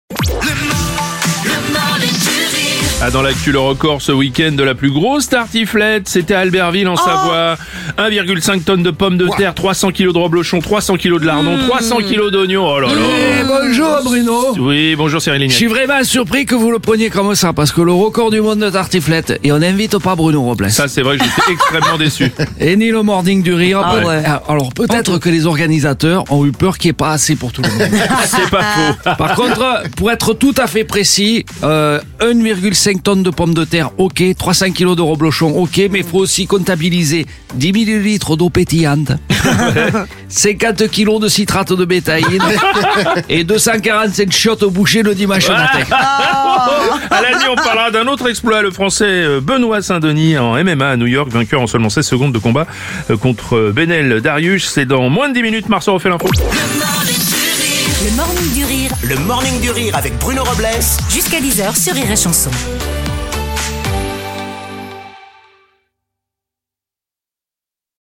L’imitateur